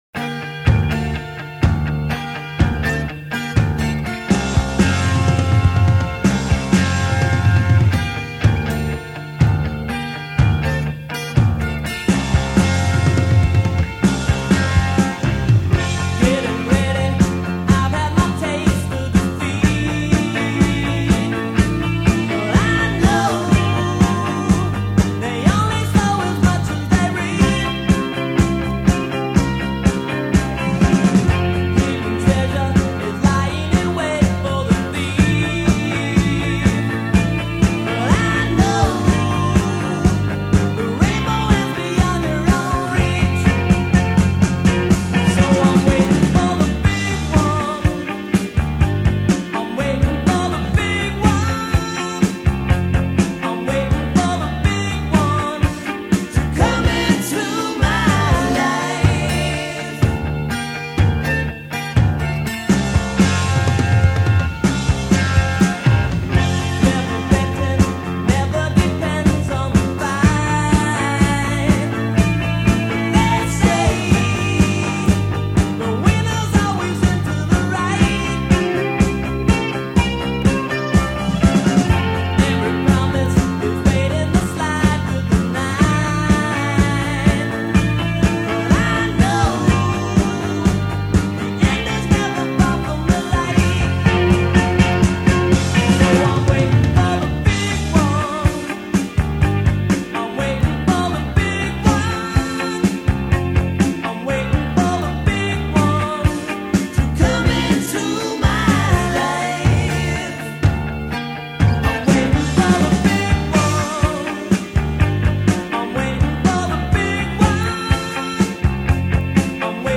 Tracks 4-6 Recorded at Cherokee Studios
Drums.
Lead Vocal and Rhythm Guitar.